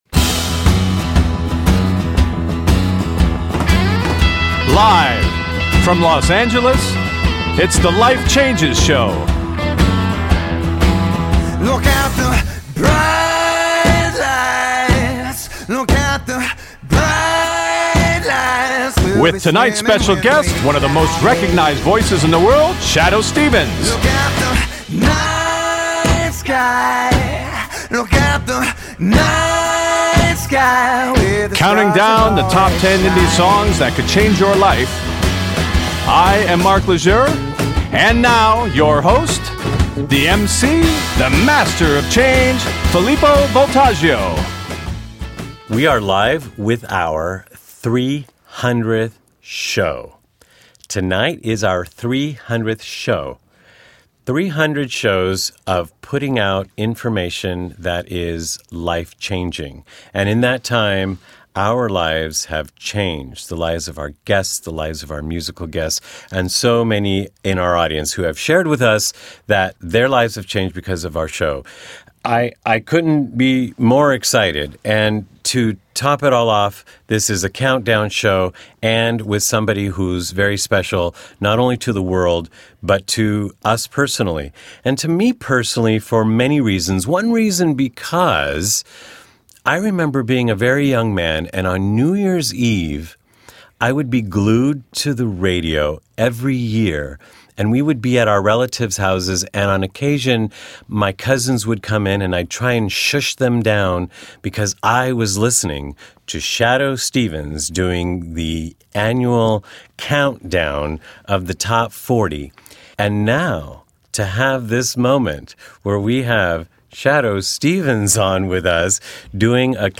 Talk Show Episode
Guest, Shadoe Stevens
recorded at Studio NoHo in North Hollywood
The songs range in style from indie rock to spoken word and electronica, with a few other genres in between.